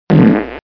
splat.mp3